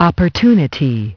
opportunity – [ op-er-too-ni-tee, -tyoo- ] – / ˌɒp ərˈtu nɪ ti, -ˈtyu- /
opportunity.mp3